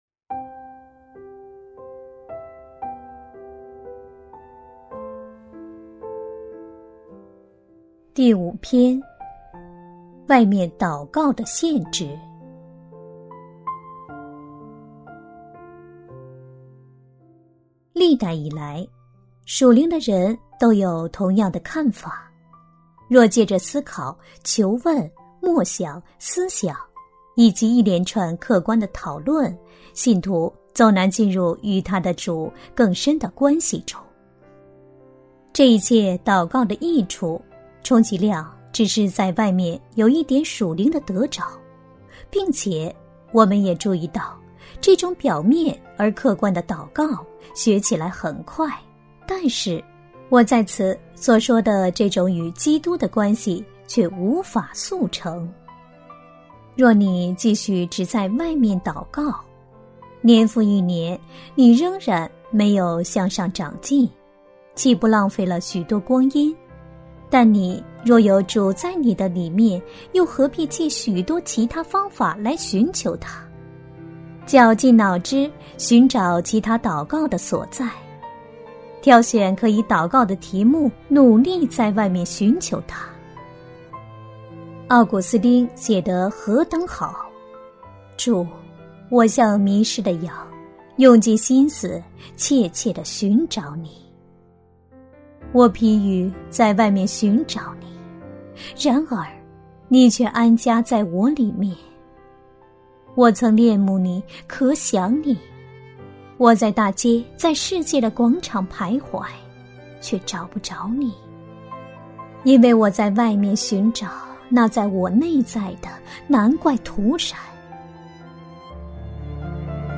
首页 > 有声书 | 灵性生活 | 灵程指引 > 灵程指引 第五篇：外面祷告的限制